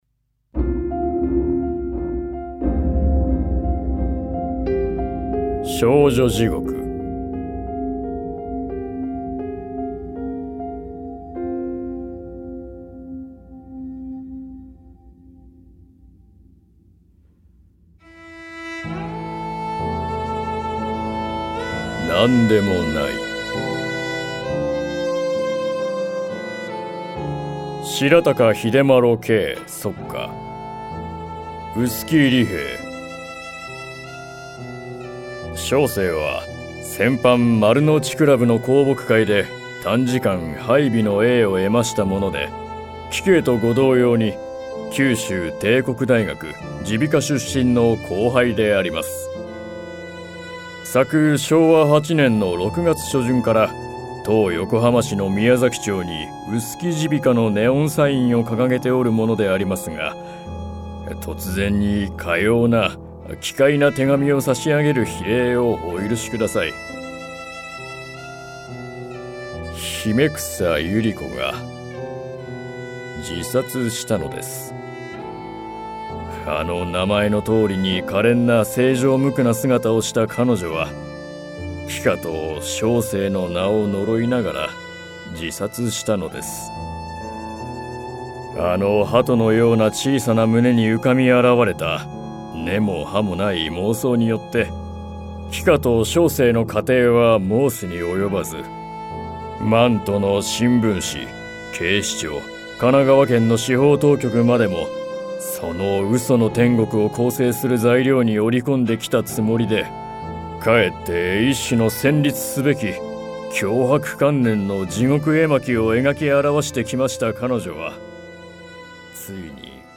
[オーディオブック] 夢野久作「少女地獄」